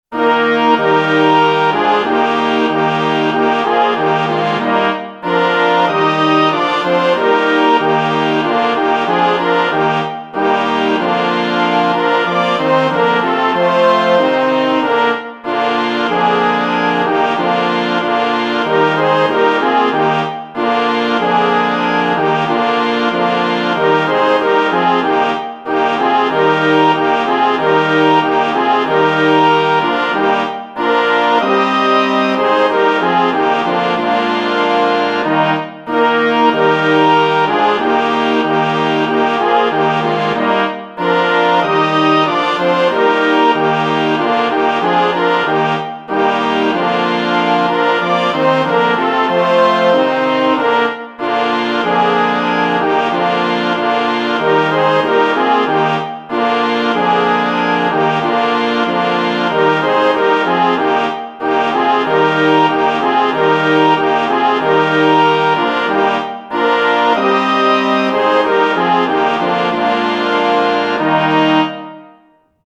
Choral
Dieser klangvolle Choral ist bestens geignet als Auftakt